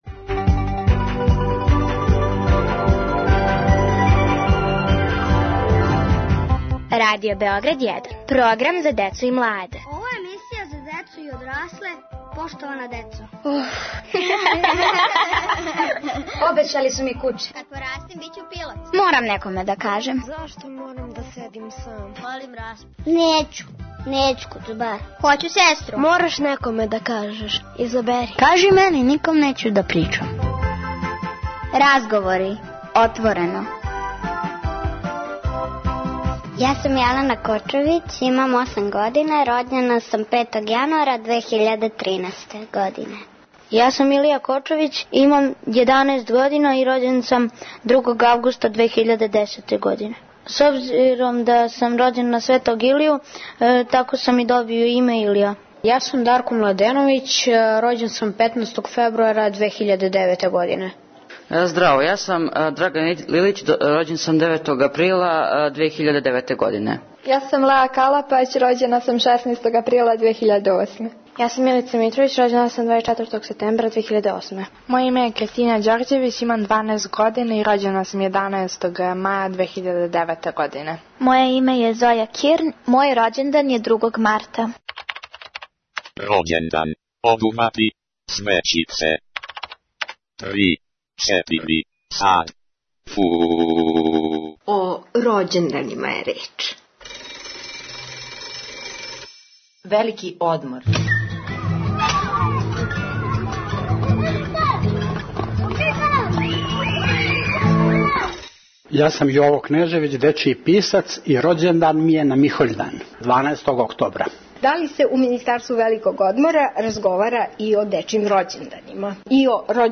Поштована деца отворено разговарају о рођенданима.